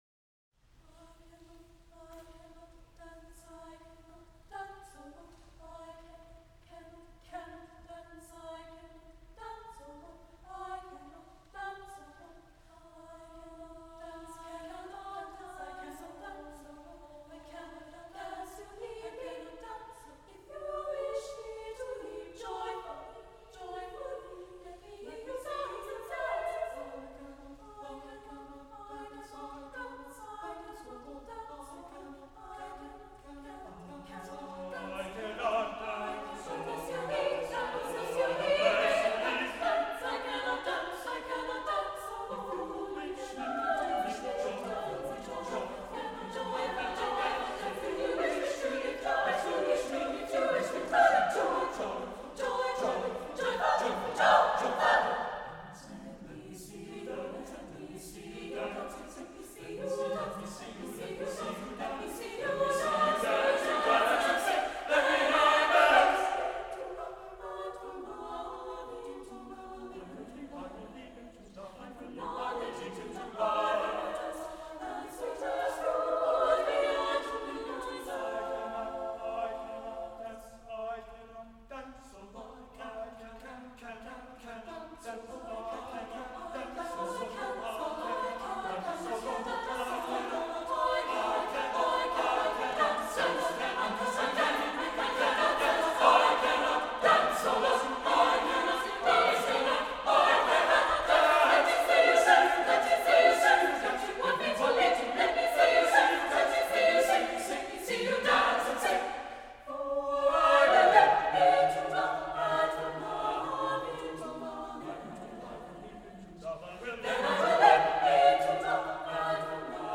csucs - Music from the CSU Chamber Singers while I was a member
main csucs / NCCO Inaugural Conference Closing Concer / 15 Ecstatic Meditations - 3.